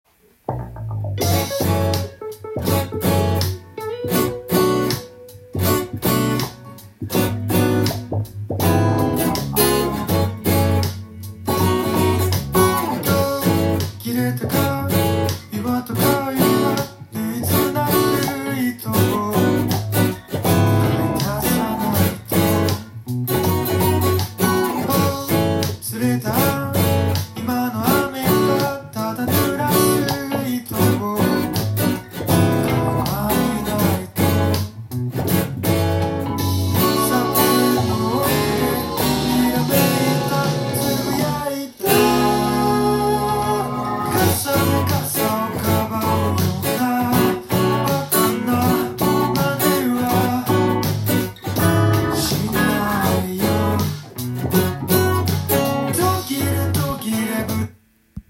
音源に合わせて譜面通り弾いてみました
４和音コードが満載です。
リズムのほうは１６分音符のハネ　になりますので